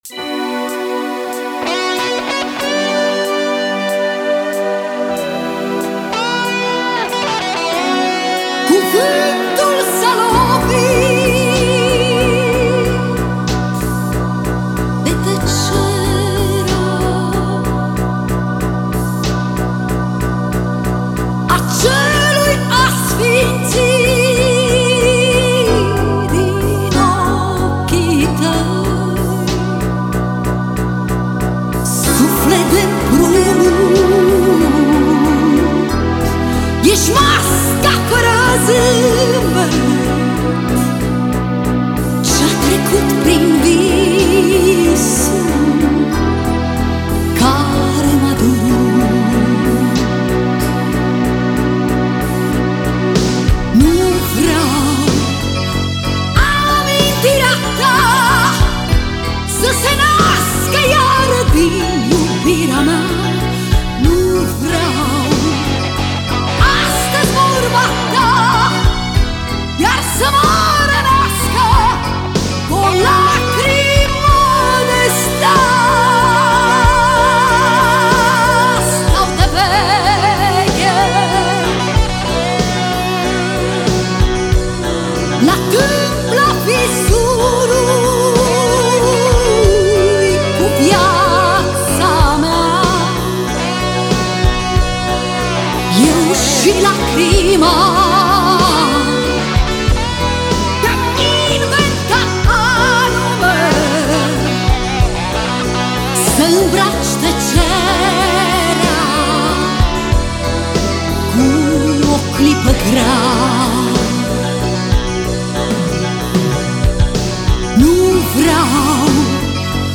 a fost un basist și compozitor român de muzică rock